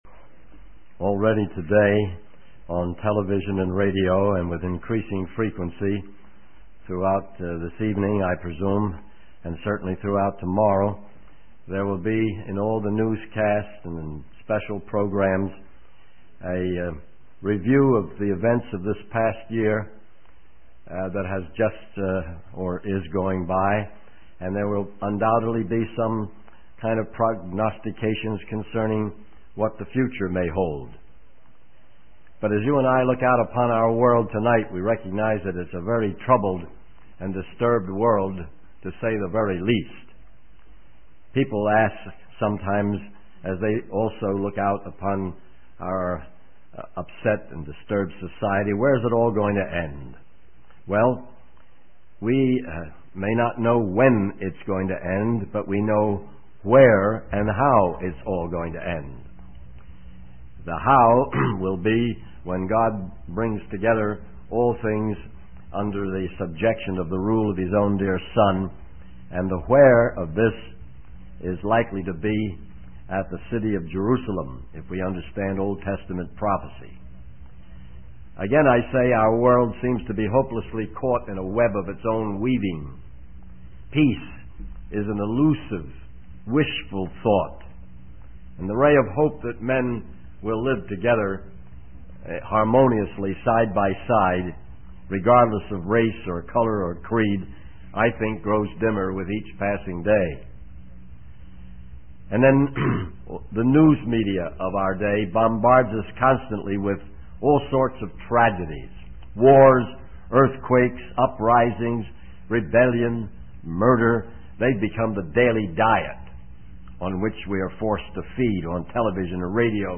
In this sermon, the speaker reflects on the troubled and disturbed state of the world and society. He acknowledges that while we may not know when it will end, we know where and how it will end, referring to the revelation and rapture. The speaker humorously shares his experience of being scheduled to speak at a youth rally and the anticipation of finally getting his turn to speak.